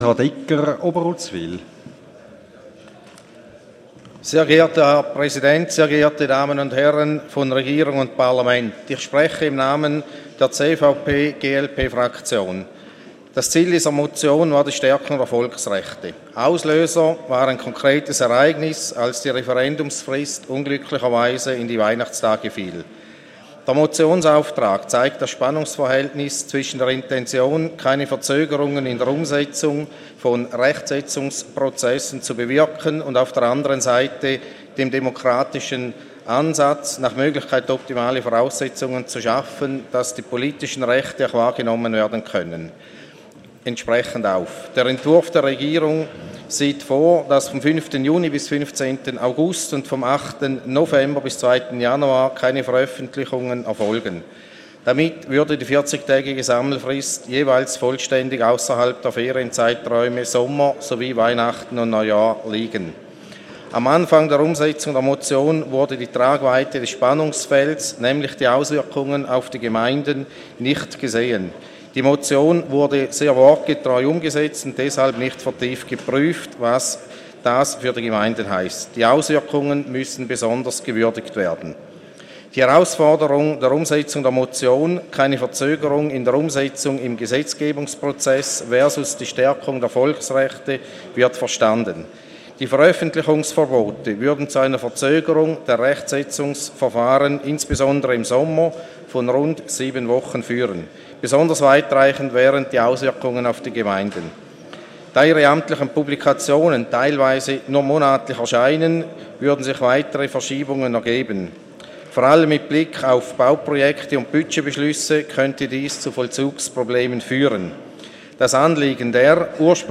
Session des Kantonsrates vom 20. und 21. Februar 2017